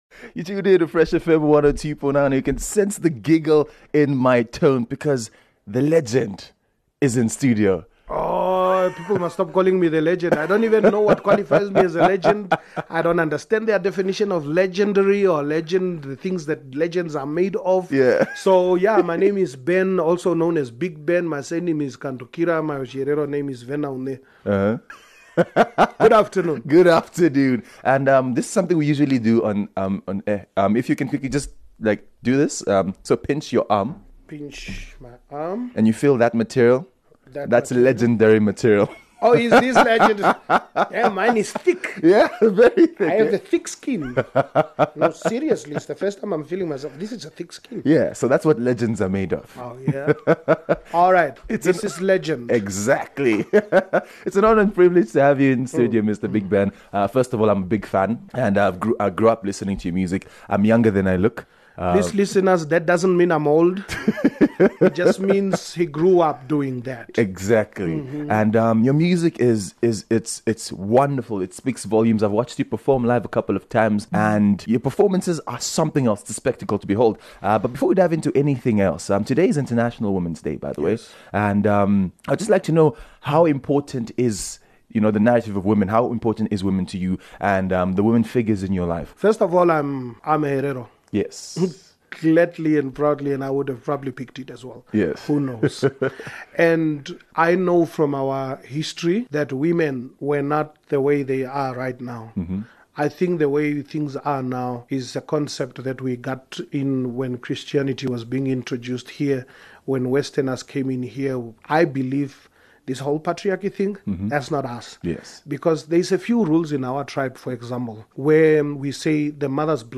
live in studio